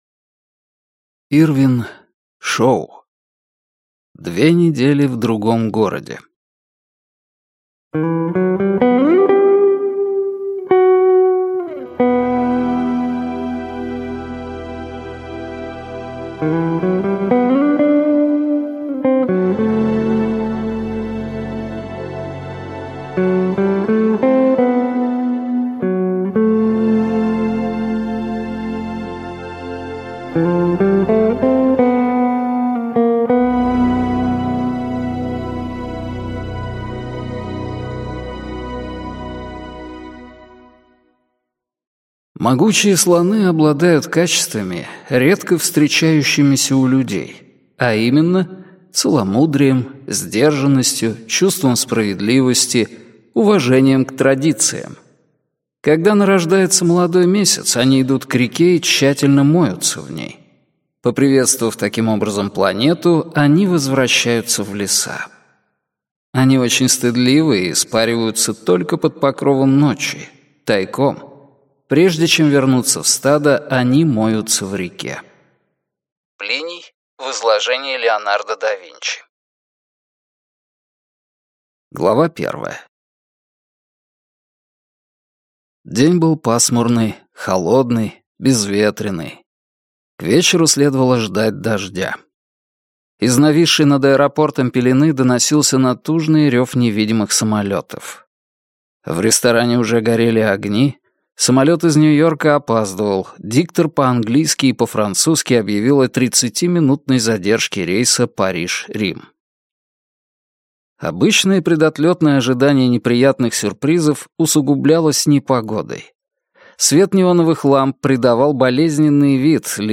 Aудиокнига Две недели в другом городе.